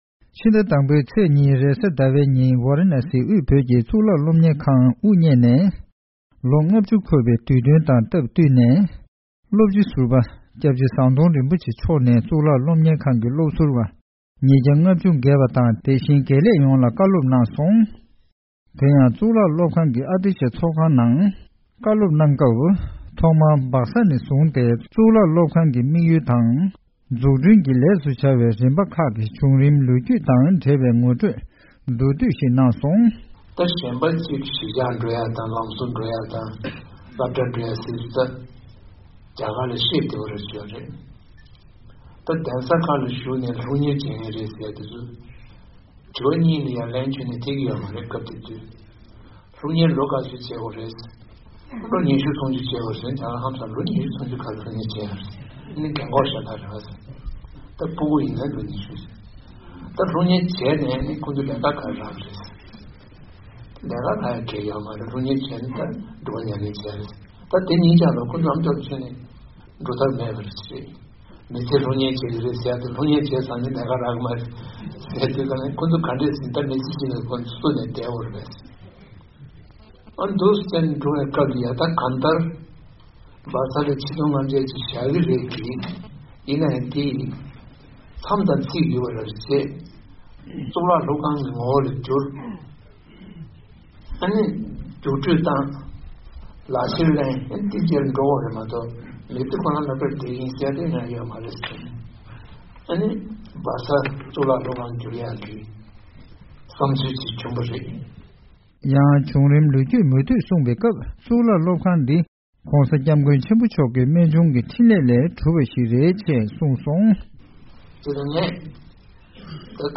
ཟམ་གདོང་རིན་པོ་ཆེའི་གསུང་བཤད།
ཝཱ་རཱ་ཎ་སཱི་དབུས་བོད་ཀྱི་གཙུག་ལག་སློབ་གཉེར་ཁང་དབུ་བརྙེད་ནས་ལོ་༥༠་འཁོར་ཡོད་ཅིང་། ད་ཐེངས་ཀྱི་དུས་དྲན་ཐོག་ཏུ་ངེས་སྟོན་པ་ཟུར་པ་སློབ་དཔོན་ཟམ་གདོང་རིན་པོ་ཆེས་ཕྱི་ཟླ་དང་པོའི་ཚེས་༢་ཉིན་དགེ་སློབ་རྣམས་དང་ མཛད་སྒོ་དེར་ཆེད་དུ་ཕེབས་པའི་སྐུ་མགྲོན་རྣམས། ད་དུང་སློབ་ཕྲུག་ཟུར་པ་བཅས་ལ་དུས་དྲན་དང་འབྲེལ་བའི་གསུང་བཤད་གནང་ཡོད་པའི་སྐོར།